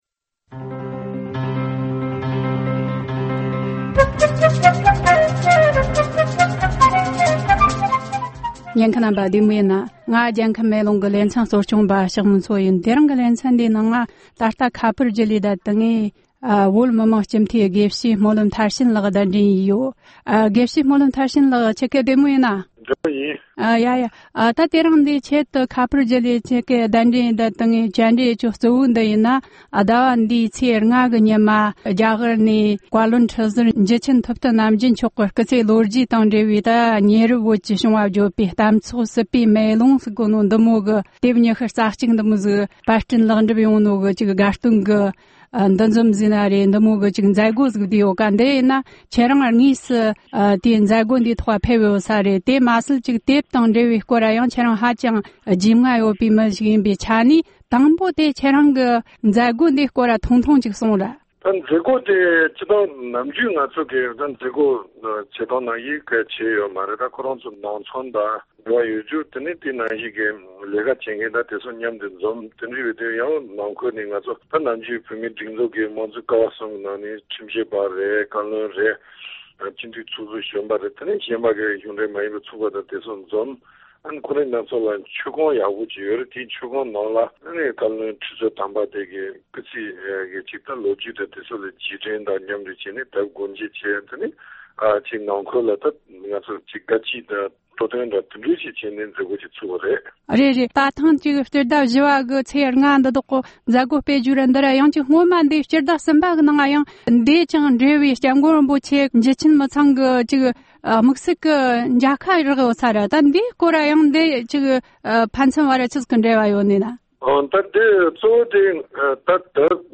བཅར་འདྲི་བྱས་བར་ཉན་རོགས་གནོངས།།